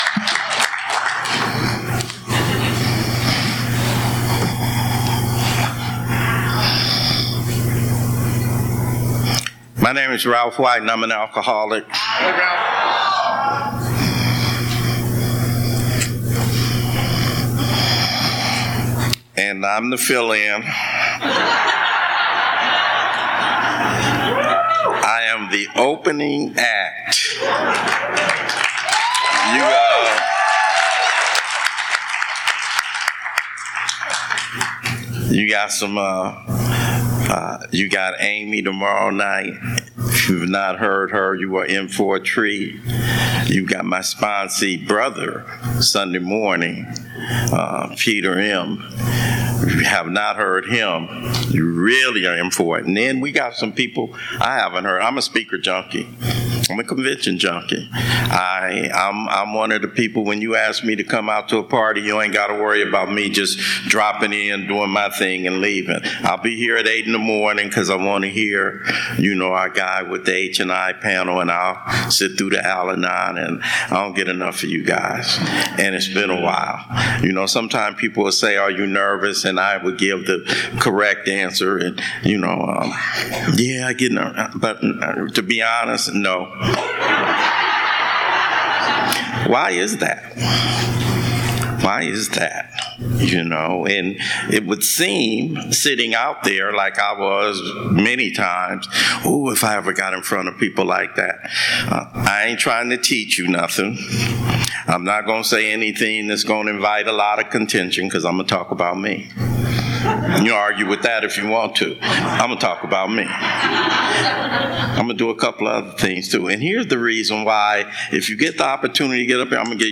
47th Annual San Fernando Valley AA Convention
Friday Night AA Opening Speaker &#8211